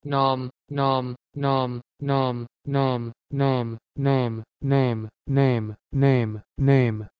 E.g. 1. Old English nama "name" had an open vowel, like "aah", but modern English "name" has a mid, front vowel, so the change was something like this (listen):
nAAm-to-name.wav